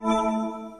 8. lock